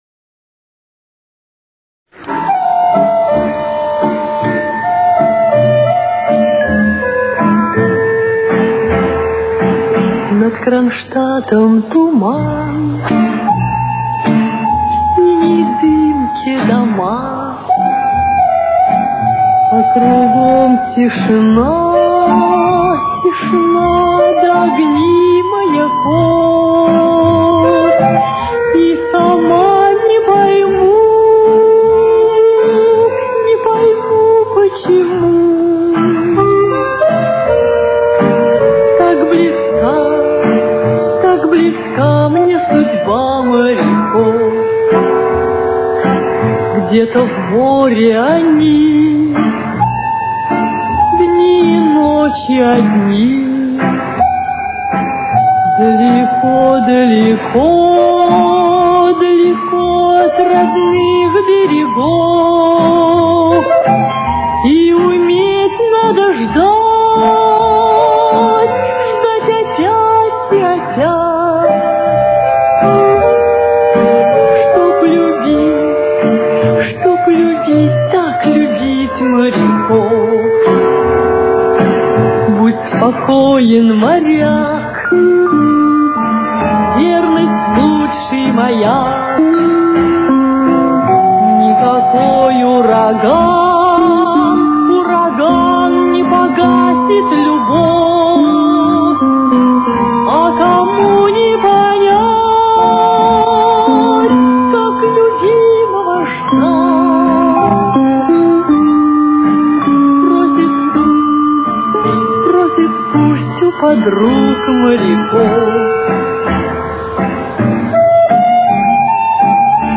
Ля минор. Темп: 54.